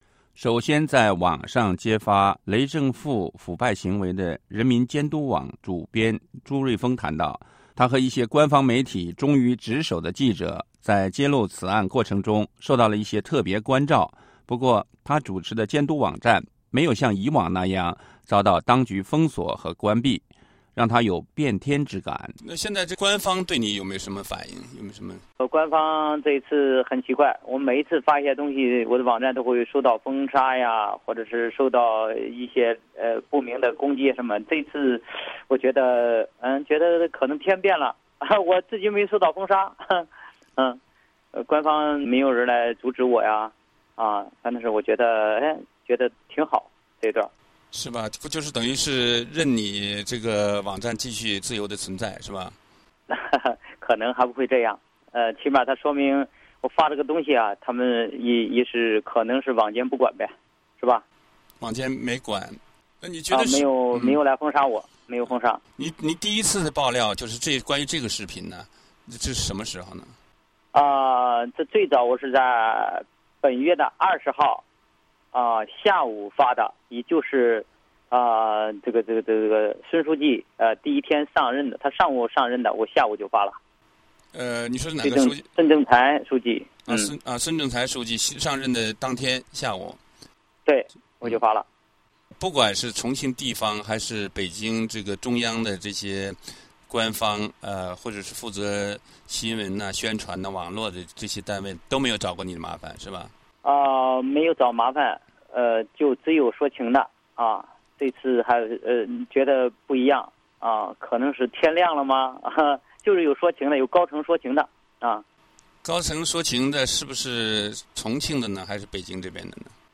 VOA专访:网曝官员不雅视频扳倒雷书记过程 (2)